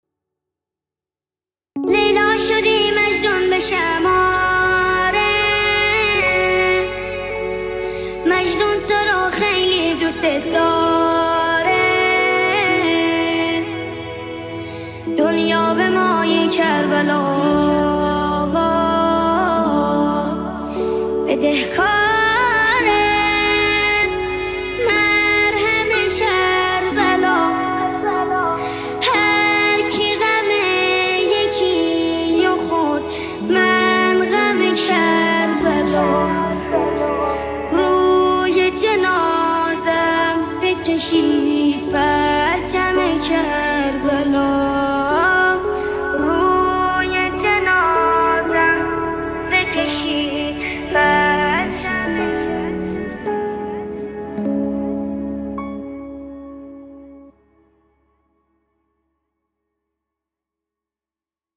شب زیارتی امام حسین (ع)